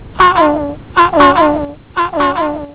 The bird.
vogel.wav